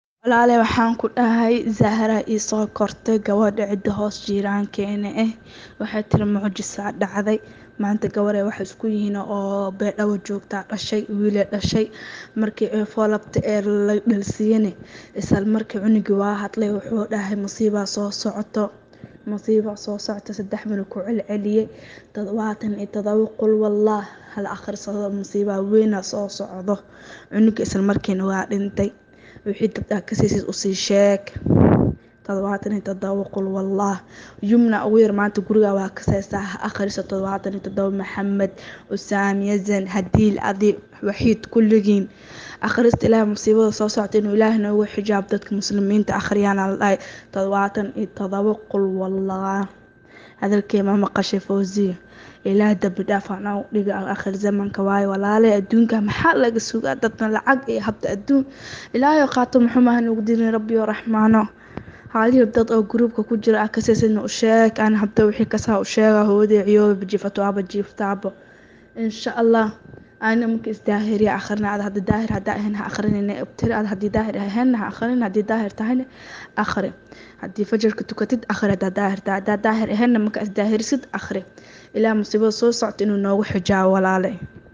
Haweneey ka hadleysa arrintan dhageyso